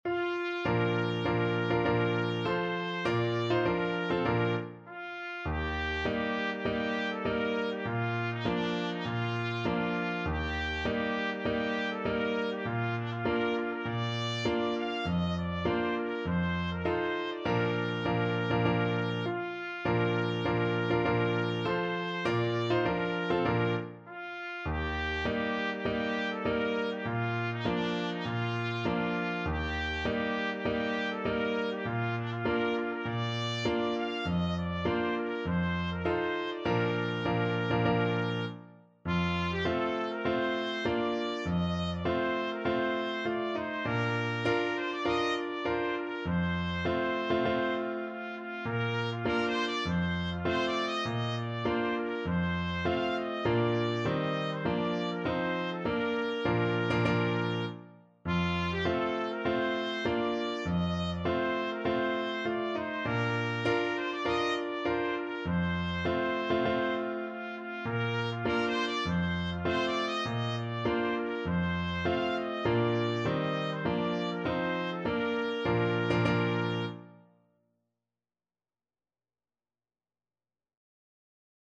Trumpet
4/4 (View more 4/4 Music)
Bb major (Sounding Pitch) C major (Trumpet in Bb) (View more Bb major Music for Trumpet )
hohenfriedberger_march_TPT.mp3